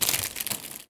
R - Foley 96.wav